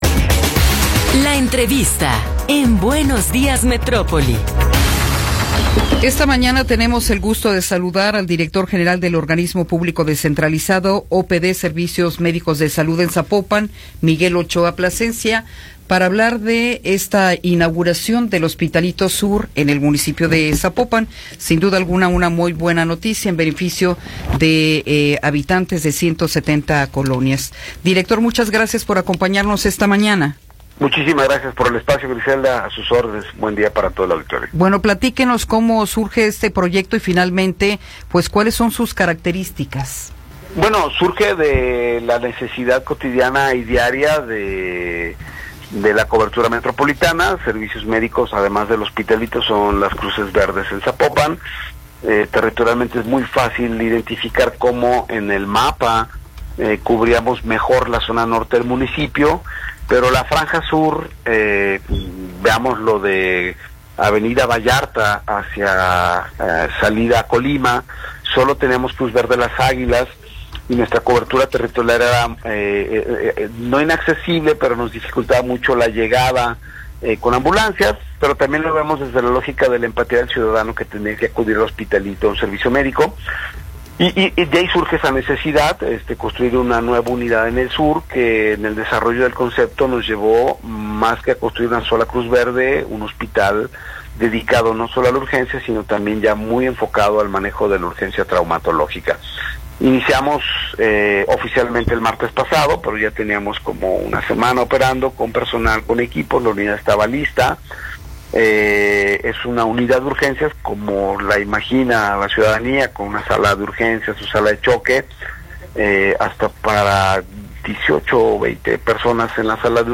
Entrevista con Miguel Ochoa Plascencia